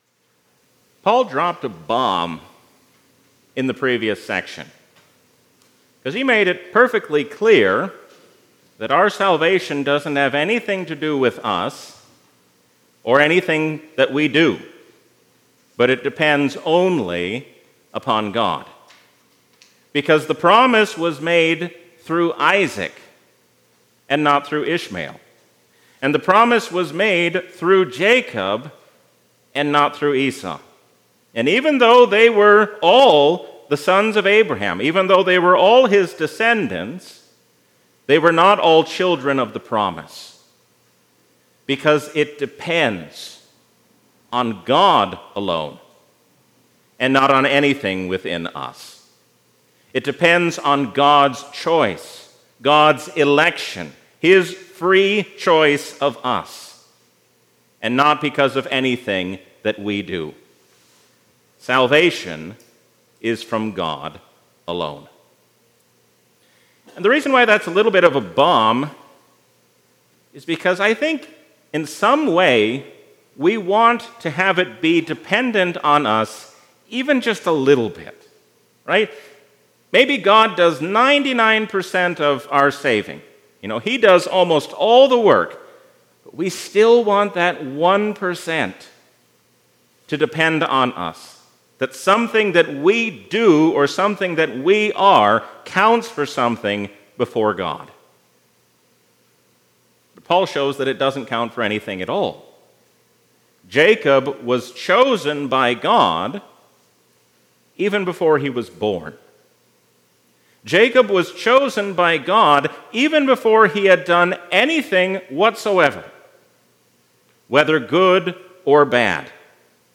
A sermon from the season "Trinity 2022."